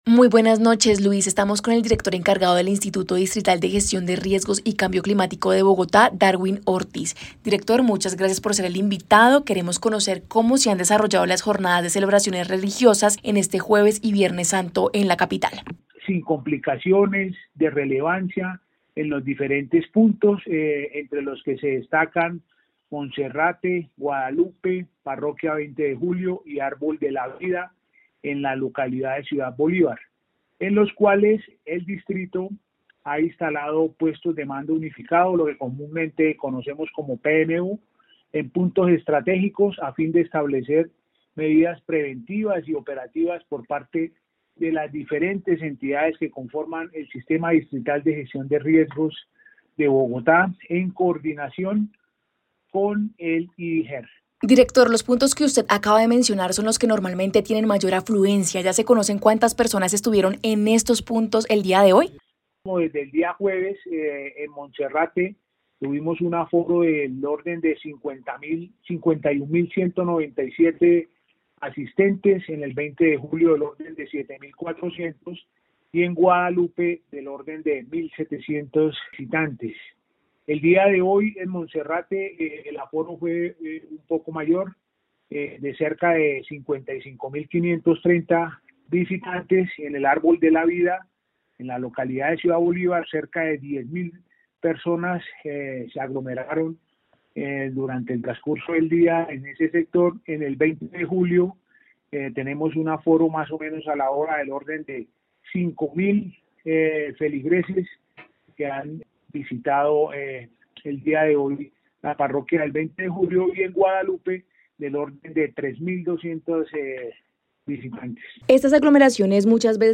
En diálogo con Caracol Radio estuvo el director encargado del Instituto de Gestión de Riesgos y Cambio Climático de Bogotá (IDIGER), Darwin Ortiz, quien nos habló sobre el balance de la masiva asistencia de los peregrinos a estos eventos durante los dos primeros días santos.